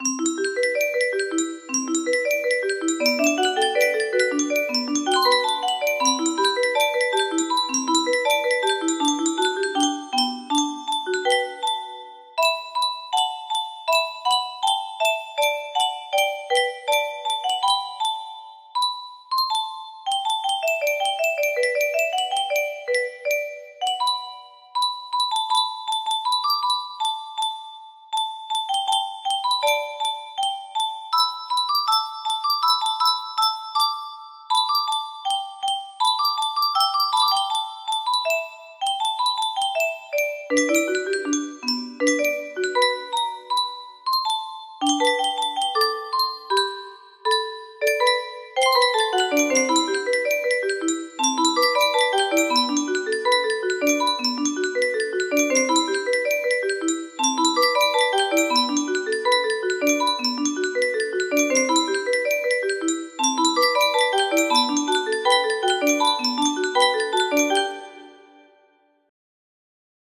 Butterfly Garden music box melody
Missing 1 low part due to 30 note music box constraints. Is 160 tempo but have it played at 2x speed to save paper when constructing for music box.)